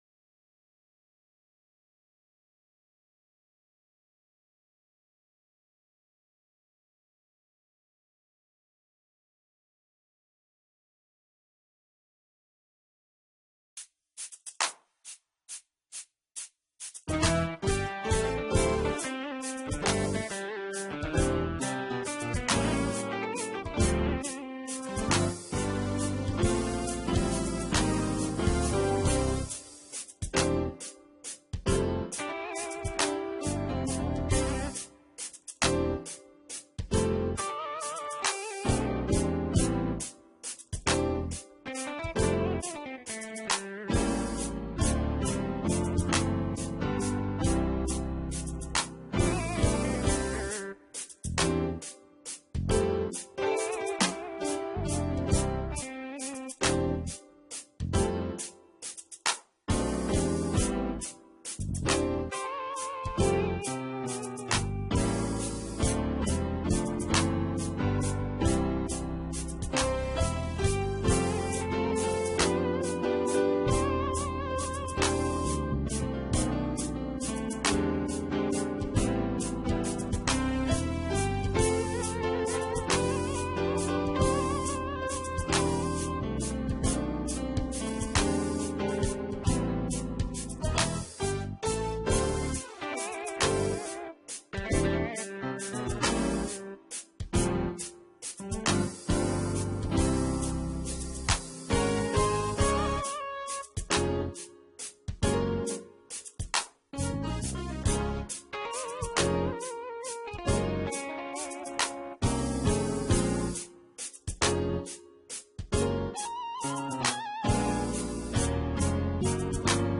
And we are glad - instrumental 2024.mp3